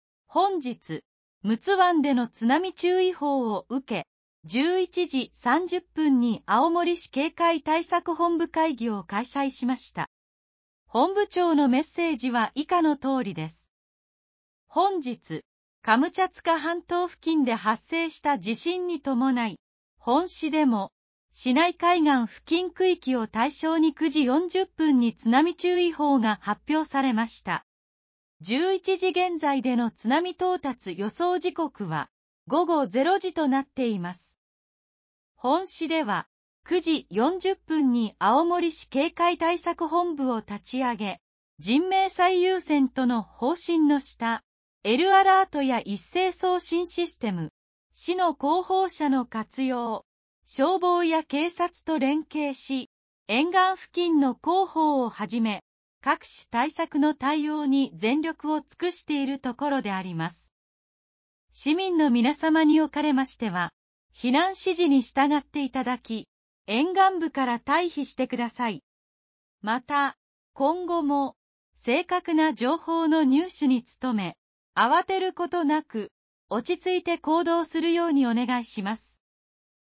本日、陸奥湾での津波注意報をうけ、11時30分に青森市警戒対策本部会議を開催しました。本部長のメッセージは以下のとおりです。